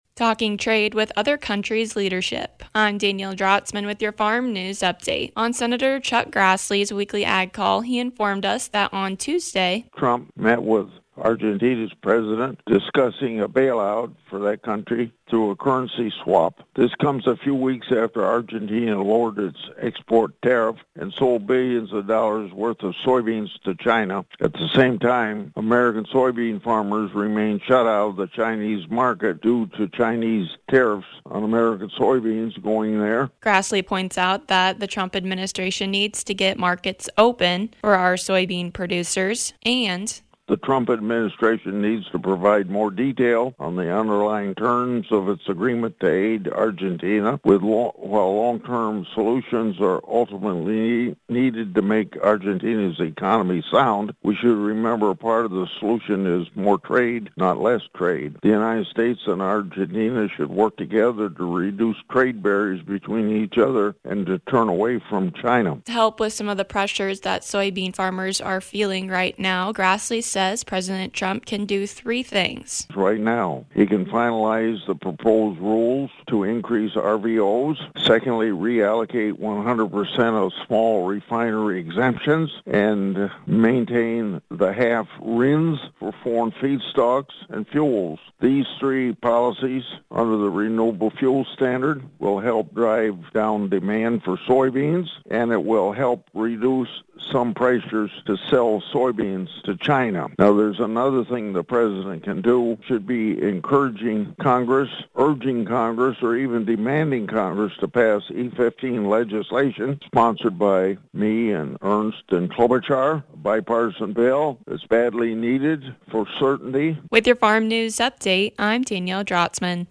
Senator Chuck Grassley gets us up to date on the Presidents trade talks with other countries leadership.